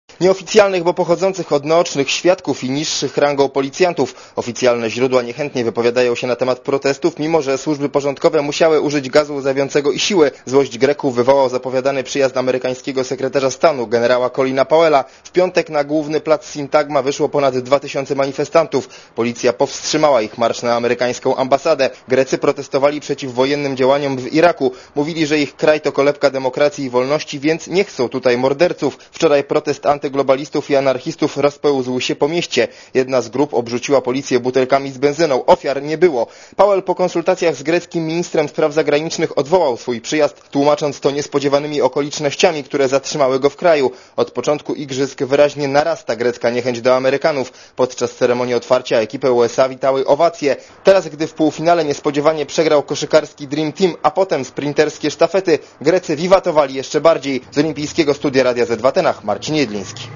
Źródło artykułu: Radio Zet